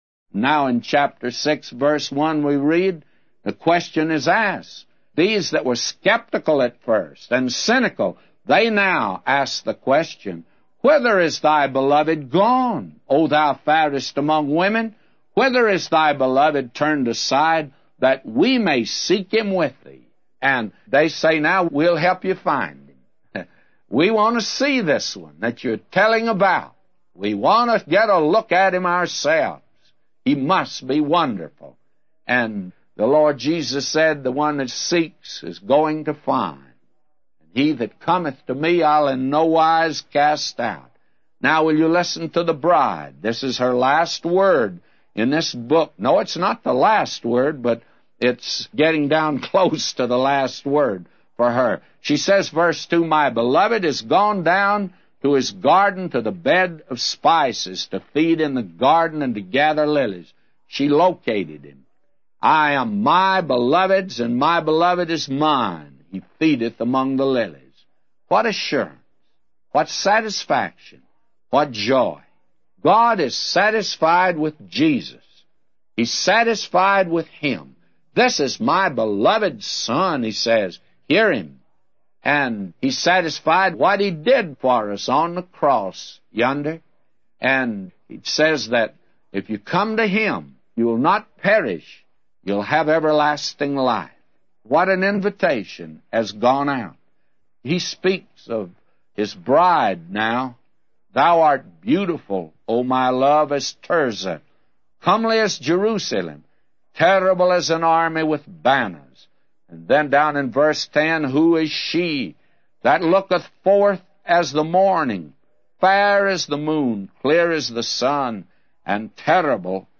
A Commentary By J Vernon MCgee For Song of Solomon 6:1-999